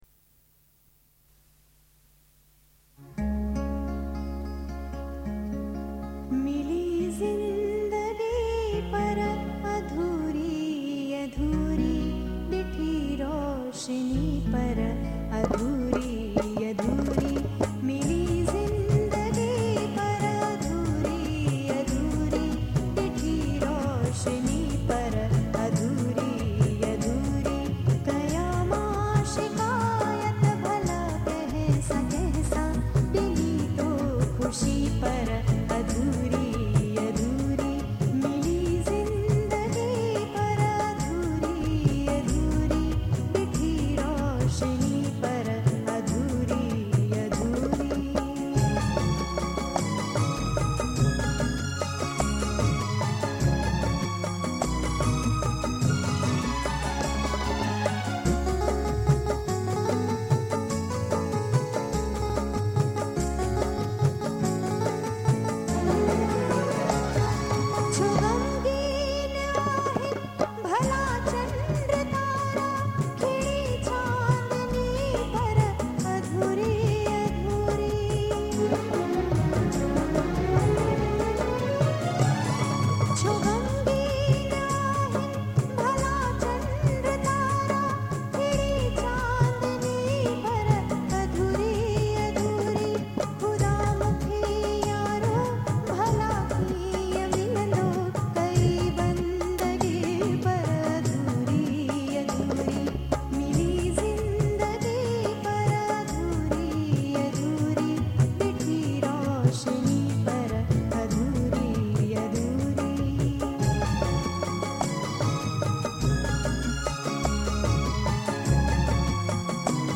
Sindhi Songs